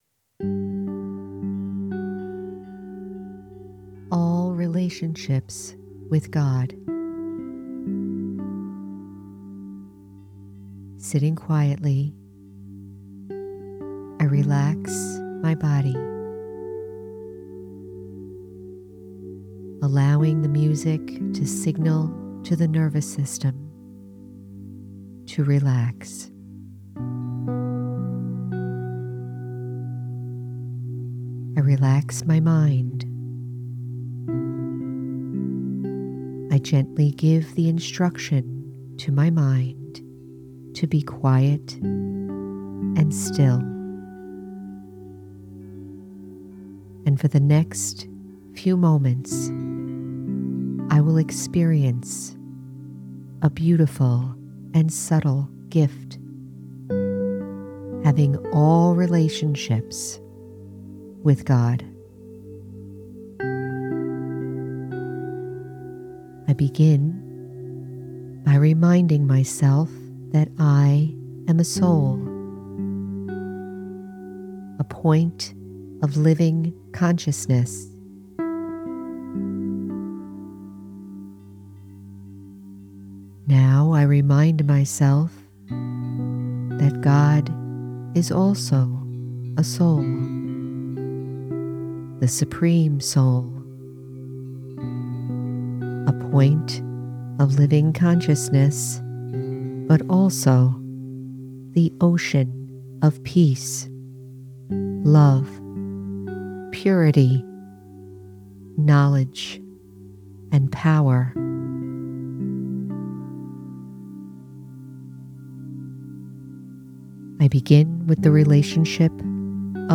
All Relationships with God- Guided Meditation- The Spiritual American- Episode 167